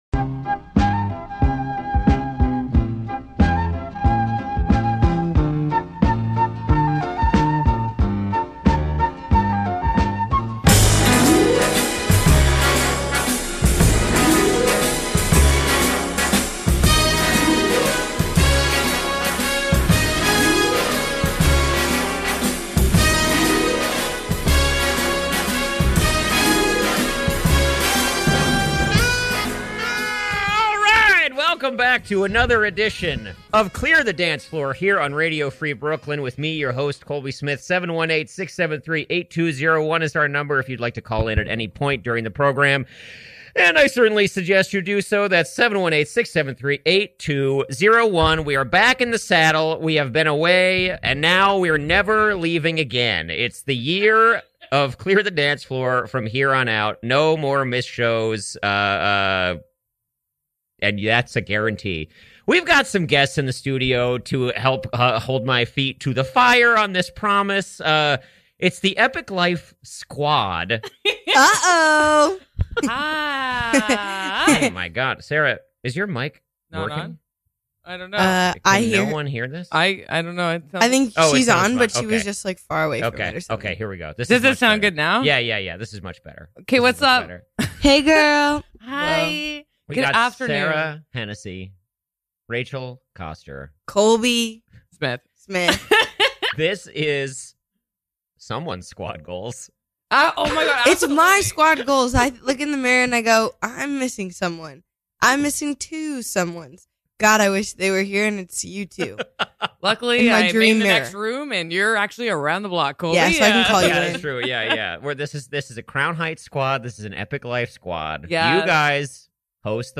They take calls, names, and spill their guts!